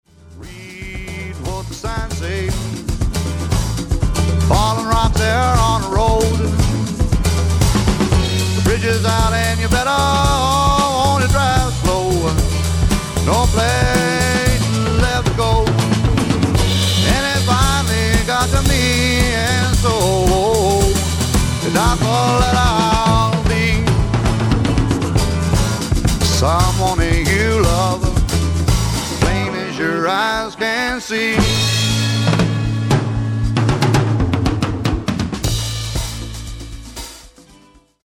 ヴォーカル、12弦ギター
ベース
パーカッション
ピアノ